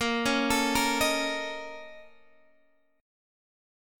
A#mM7bb5 Chord